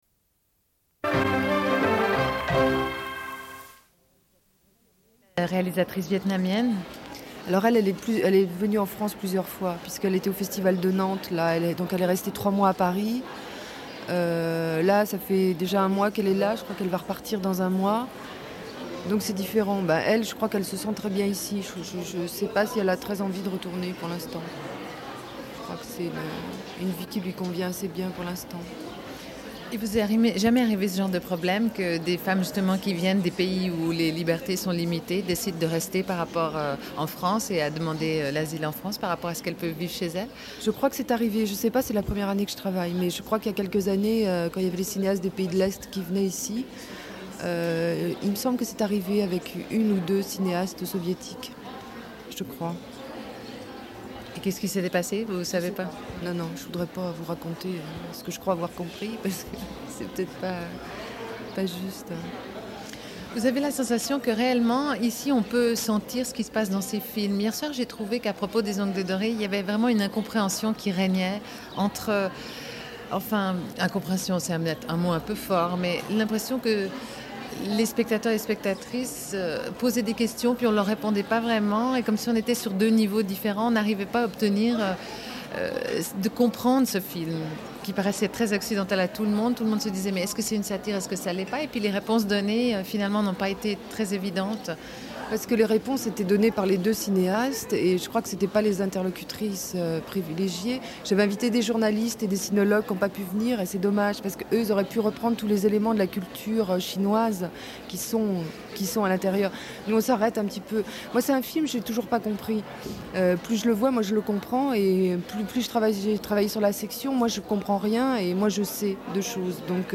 Une cassette audio, face B00:29:03
Suite de l'émission sur le Festival de films de femmes de Créteil, probablement le treizième. Le début de ce sujet semble tronqué. Diffusion d'entretiens et d'enregistrements de prises de parole captées pendant le Festival.